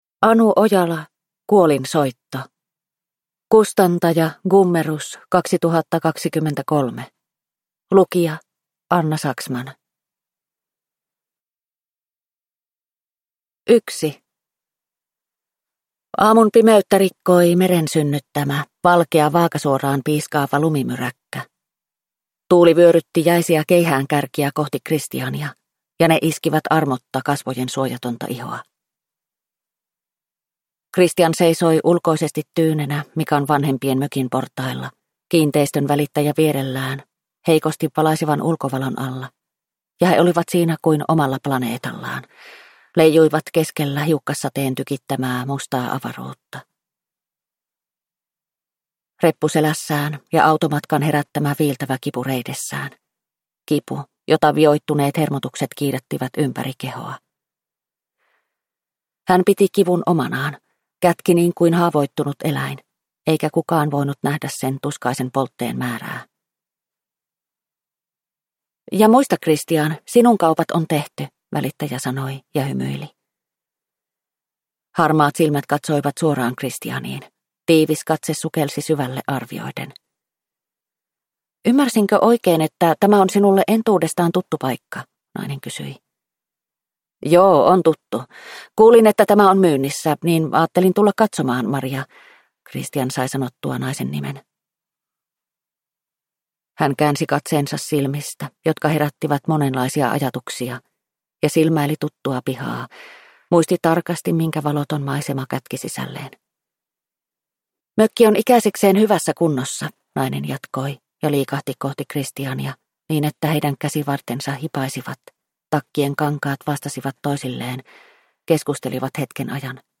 Kuolinsoitto – Ljudbok – Laddas ner